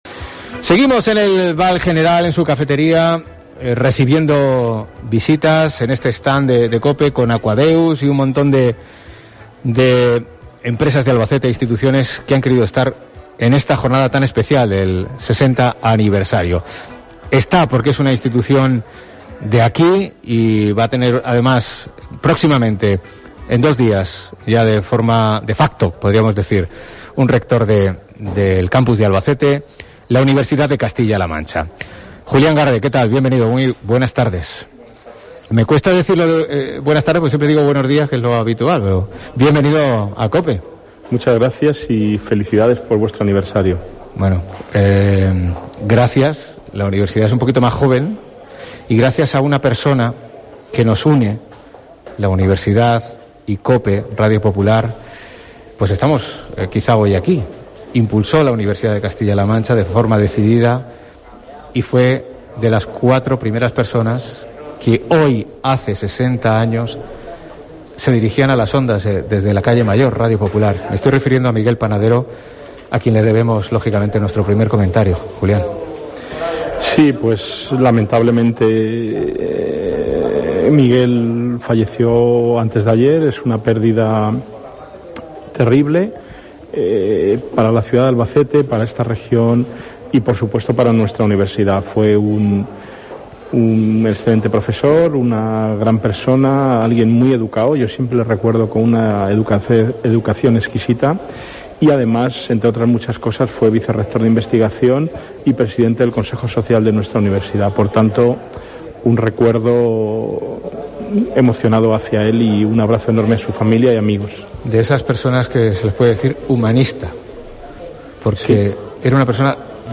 AUDIO: Entrevista con Julián Garde en COPE Albacete